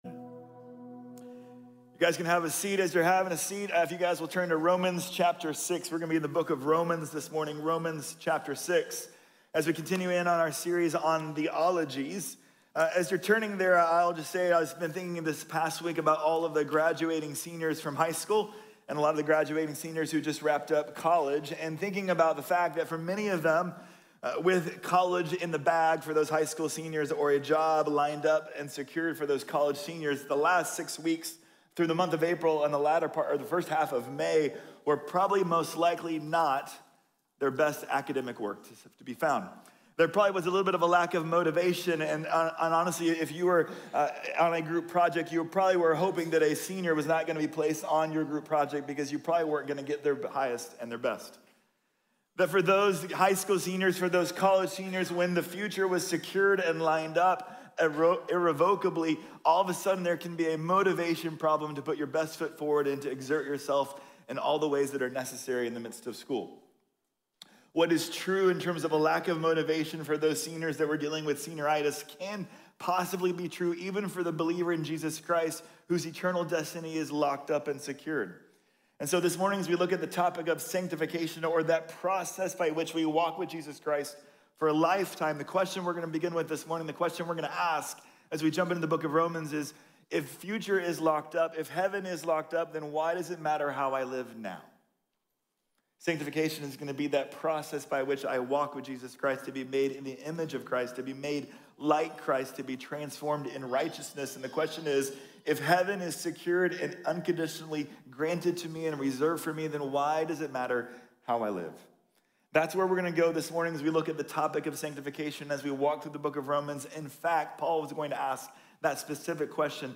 Santificación | Sermón | Iglesia Bíblica de la Gracia